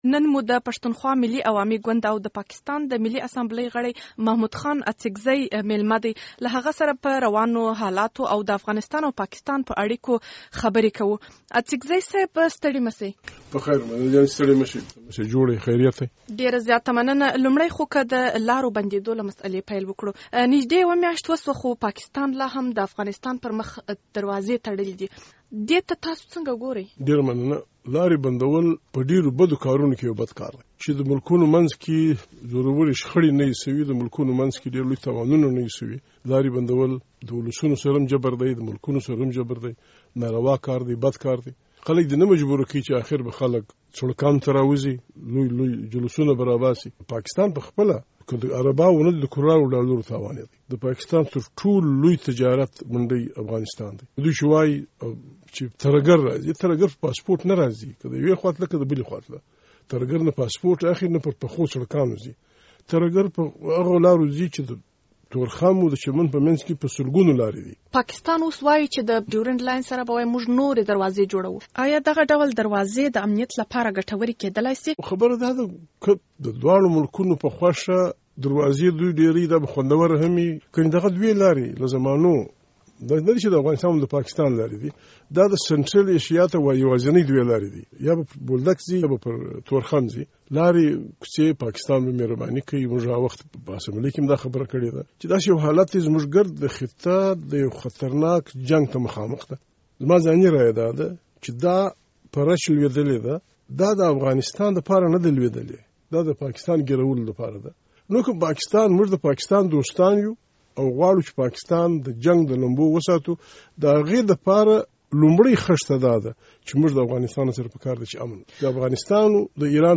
له محمود خان اچکزي سره ځانګړې مرکه